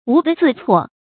無地自厝 注音： ㄨˊ ㄉㄧˋ ㄗㄧˋ ㄘㄨㄛˋ 讀音讀法： 意思解釋： 猶無地自容。